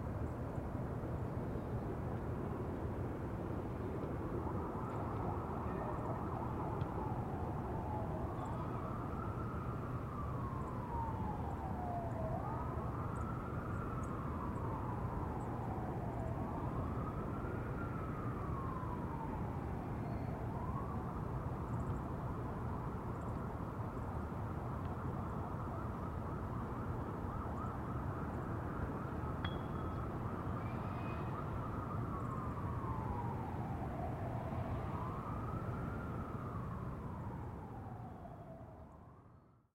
Атмосферный звук города, где недалеко случился теракт, слышны сирены